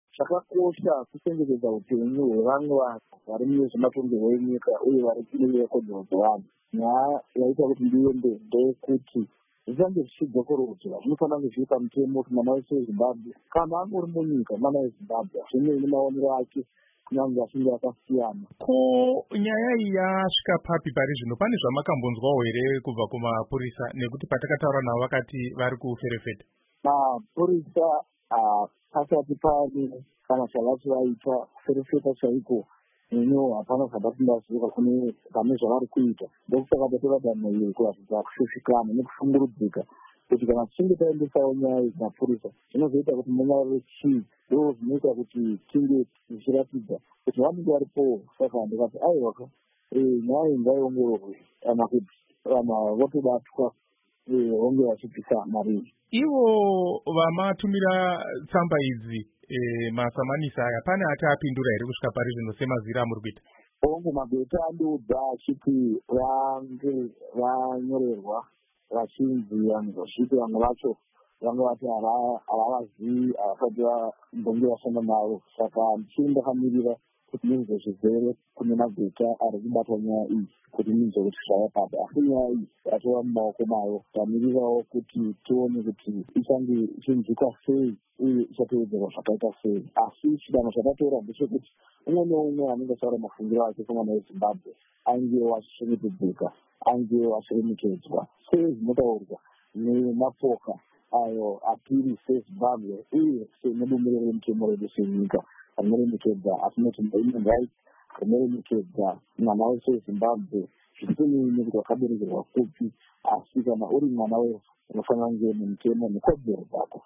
Hurukuro naVaTakudzwa Ngadziore